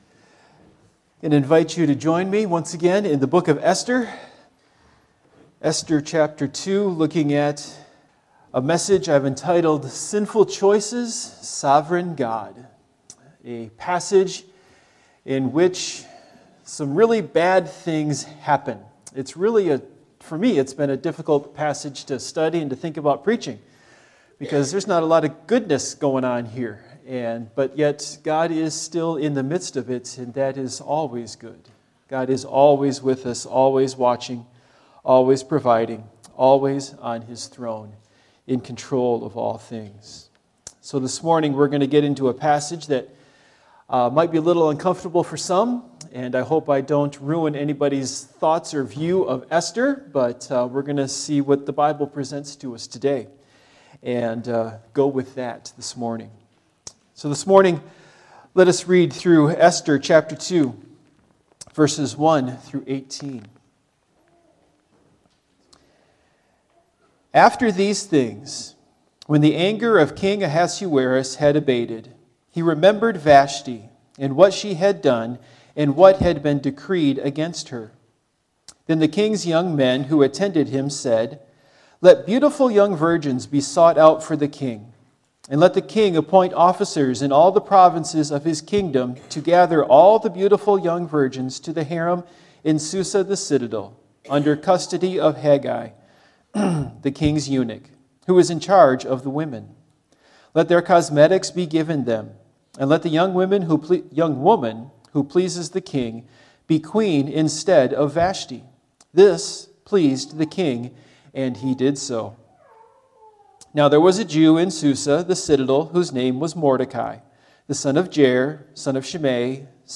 Esther Passage: Esther 2:1-18 Service Type: Morning Worship « Drunkenness